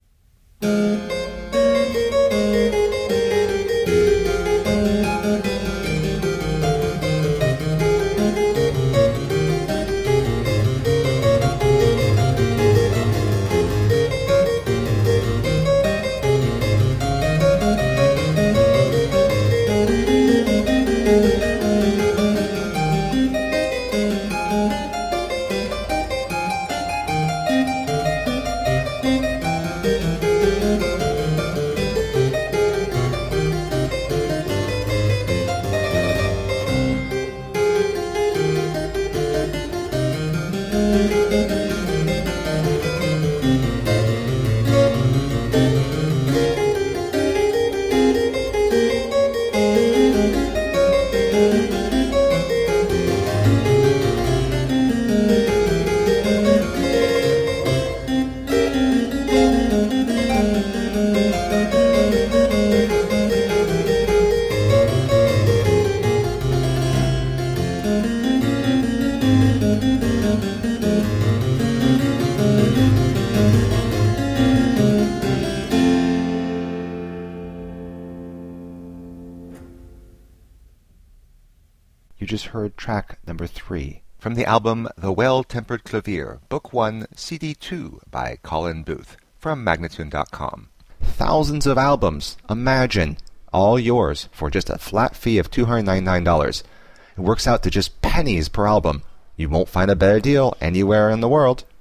Solo harpsichord music.